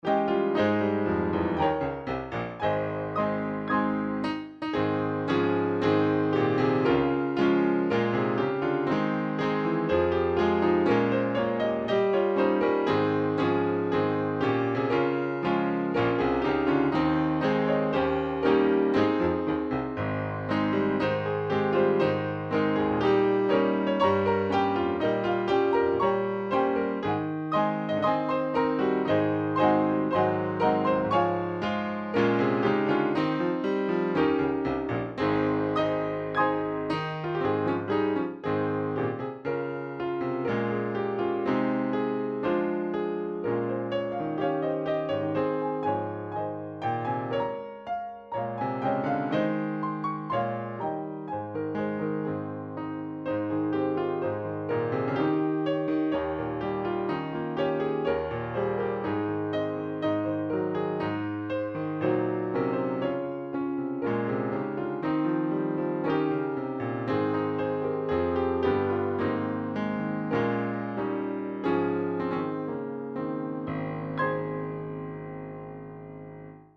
Key: A♭